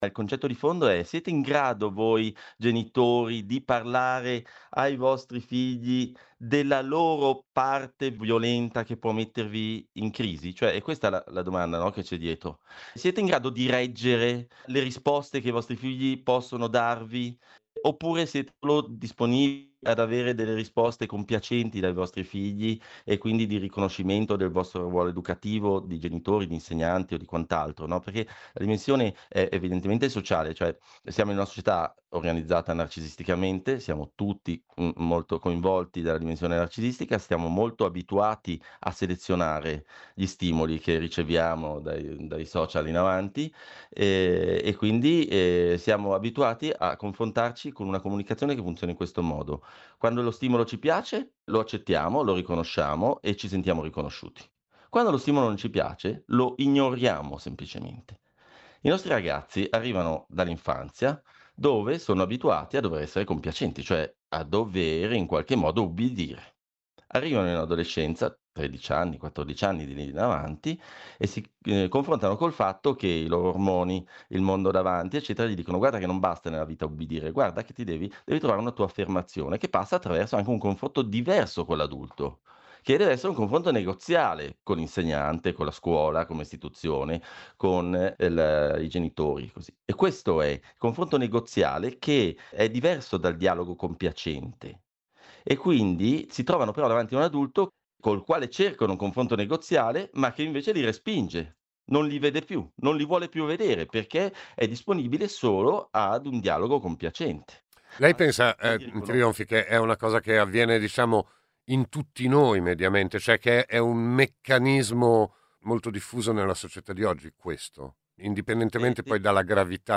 La paura (dei genitori e degli adulti) della propria imperfezione, la difficoltà di “reggere” le risposte scomode dei figli adolescenti. L'intervista